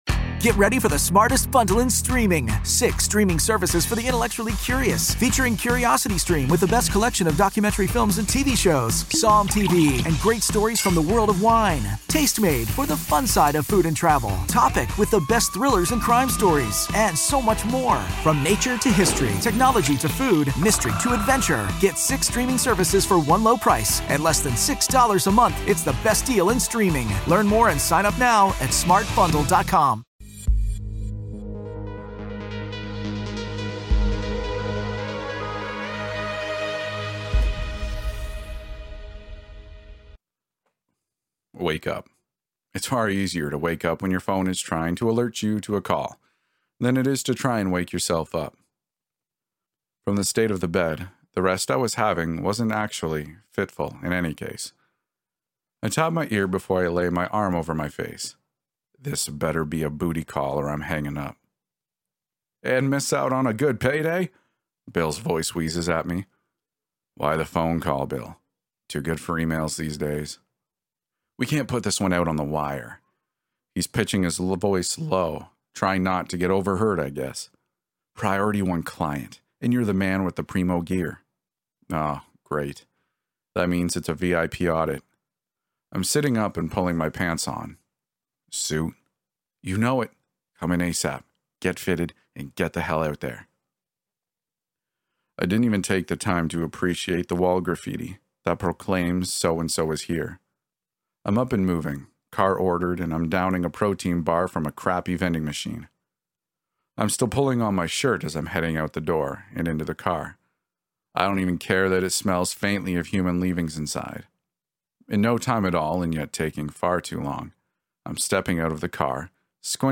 Episode 200 | Anonymity: WH_Zero | Chapter 6 | Audiobook